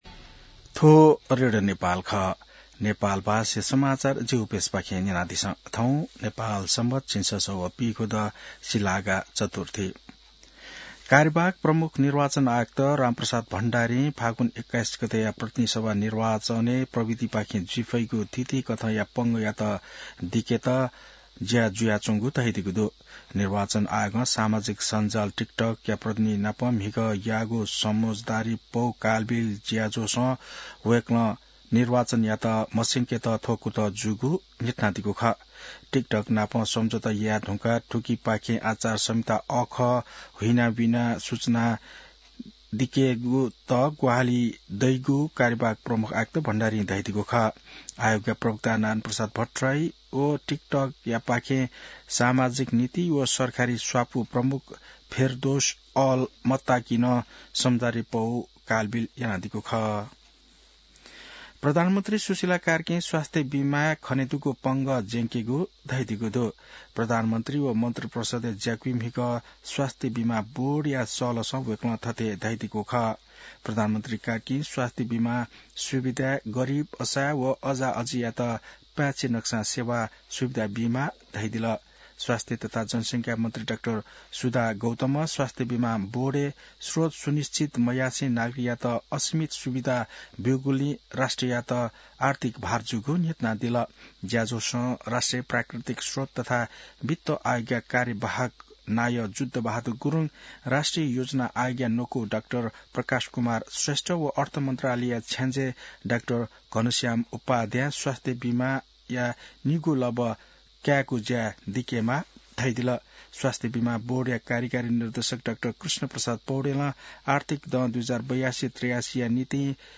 An online outlet of Nepal's national radio broadcaster
नेपाल भाषामा समाचार : २२ माघ , २०८२